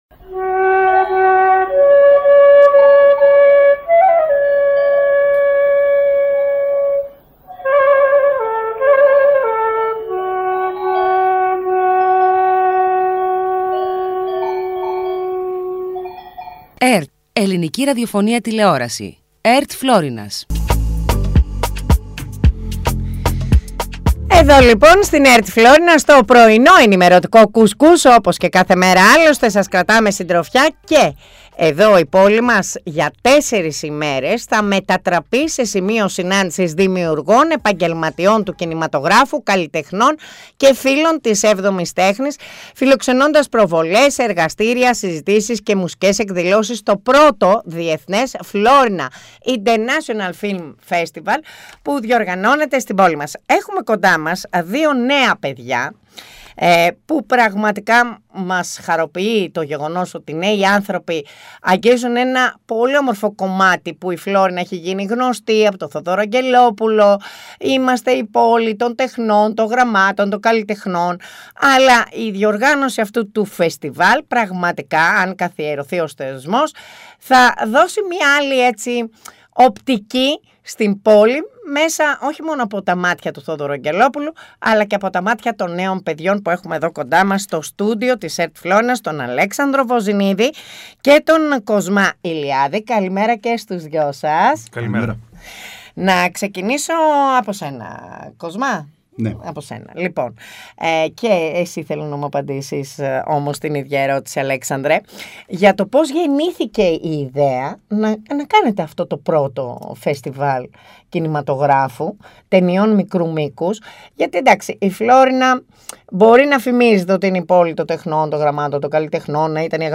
Καλεσμένοι στην εκπομπή οι διοργανωτές φεστιβάλ
ΣΥΝΕΝΤΕΥΞΗ